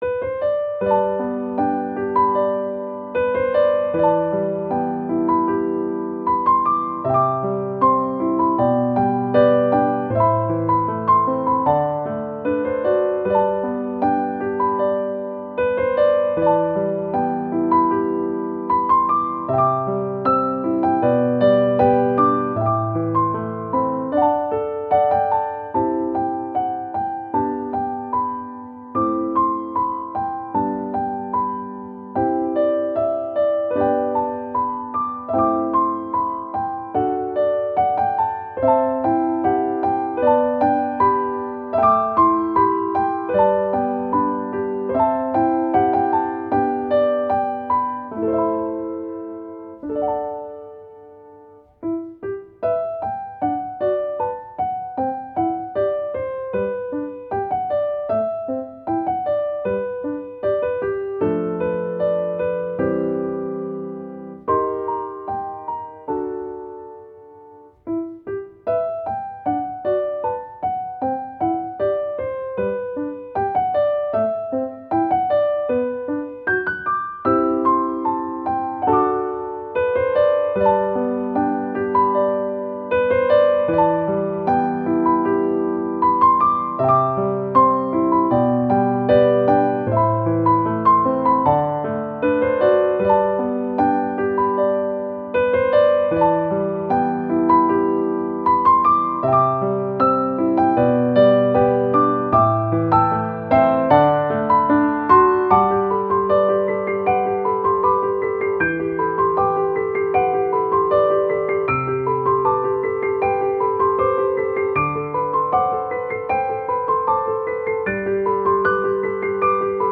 -oggをループ化-   切ない きれい 3:13 mp3